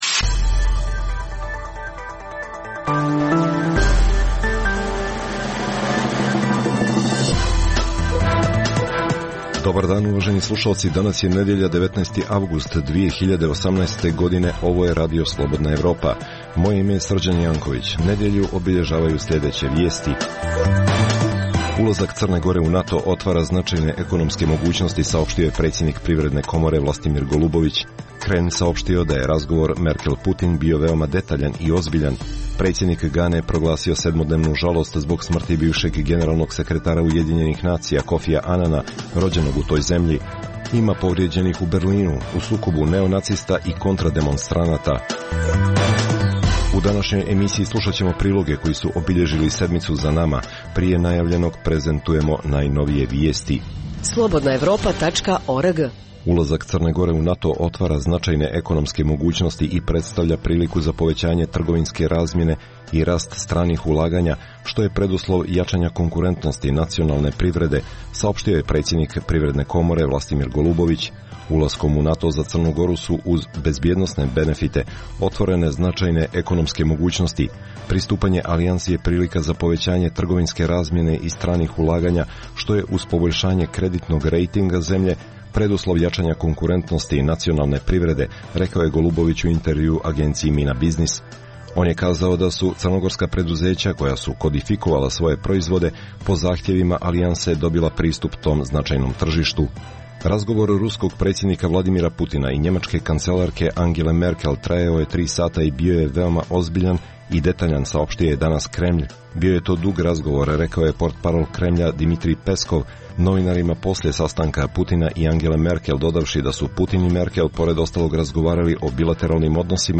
Emisija namijenjena slušaocima u Crnoj Gori. Sadrži lokalne, regionalne i vijesti iz svijeta, rezime sedmice, tematske priloge o aktuelnim dešavanjima u Crnoj Gori i temu iz regiona.